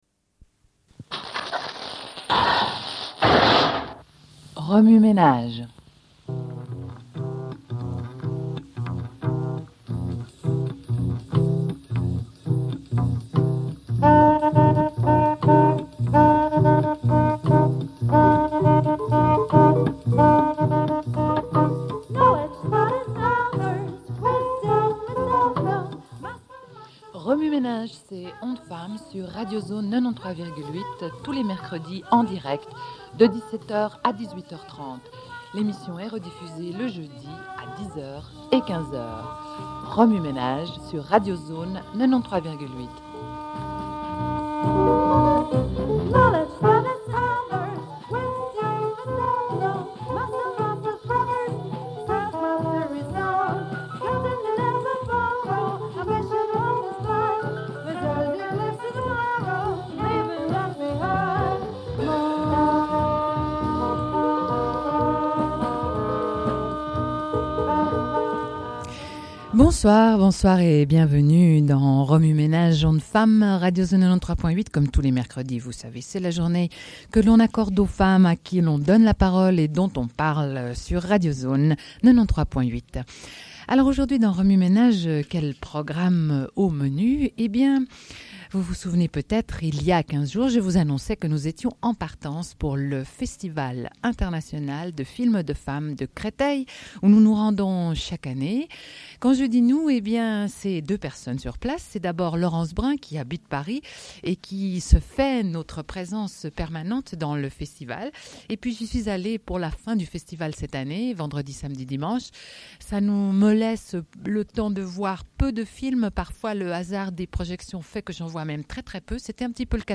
Sommaire de l'émission : autour du Festival International de films de femmes de Créteil. Diffusion d'enregistrements et d'entretiens réalisés sur place.